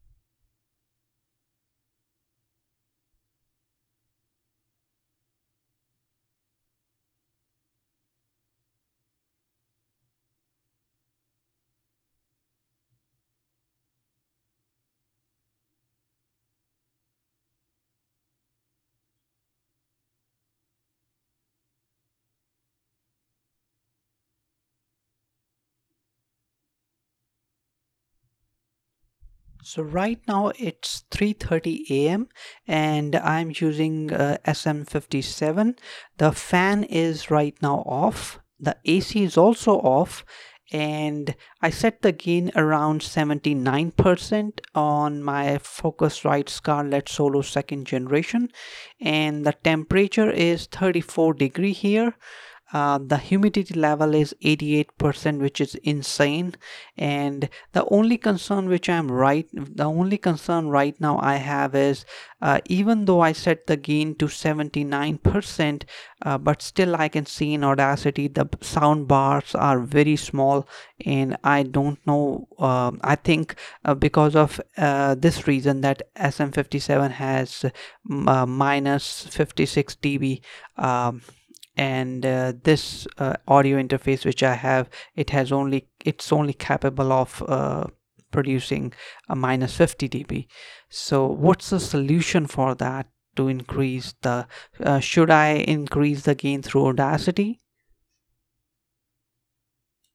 View attachment 79% gain_SM57__New.mp3
I Normalize this at -1db and then use Noise Reduction effect but waveform wasn't similar like yours...